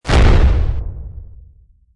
Download Musket sound effect for free.
Musket